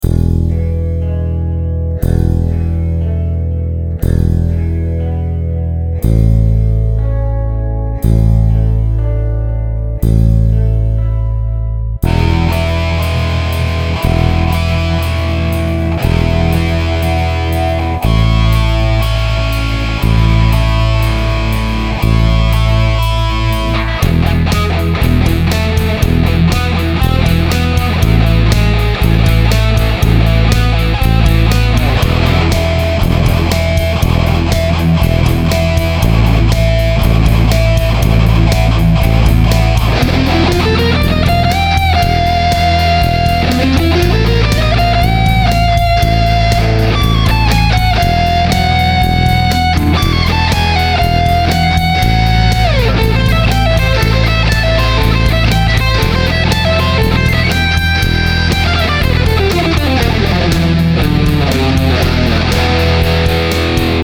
Some metallish thing…